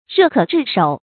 熱可炙手 注音： ㄖㄜˋ ㄎㄜˇ ㄓㄧˋ ㄕㄡˇ 讀音讀法： 意思解釋： 火熱可以灼手。比喻權勢顯赫。